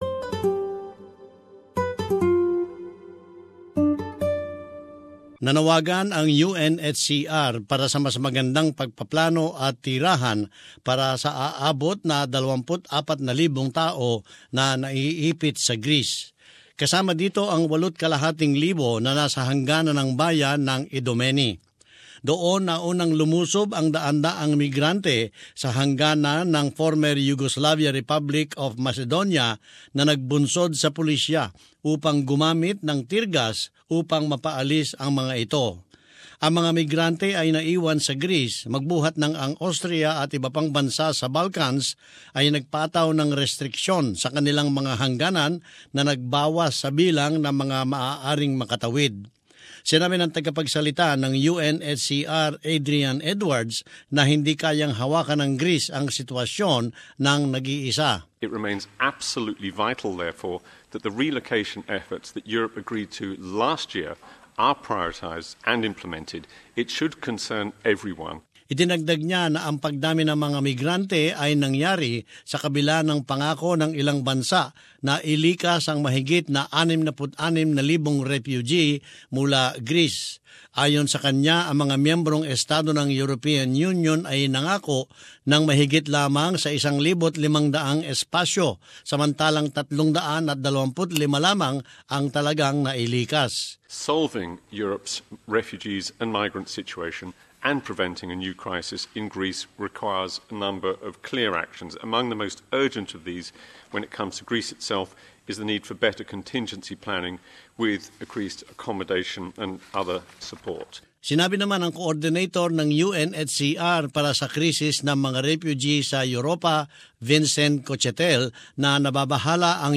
In this report, it has prompted renewed calls for border controls to be re-established in the Schengen, or passport-free, zone.